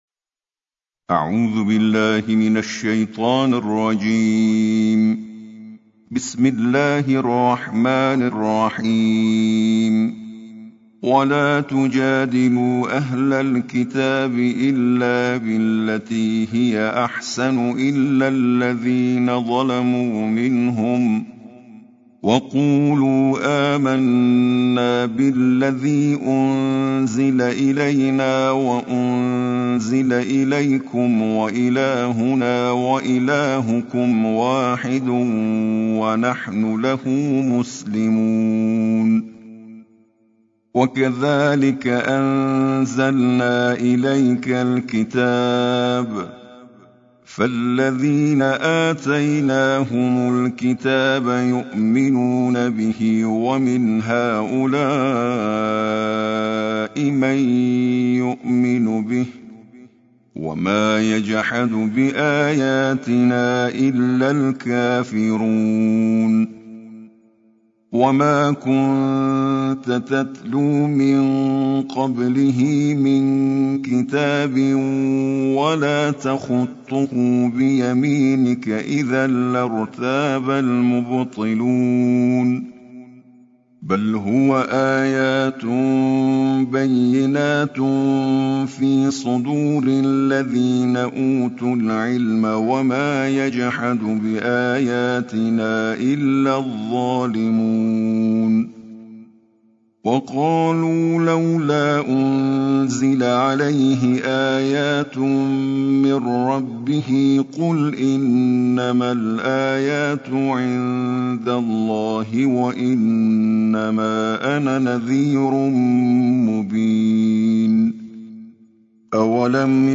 ترتيل القرآن الكريم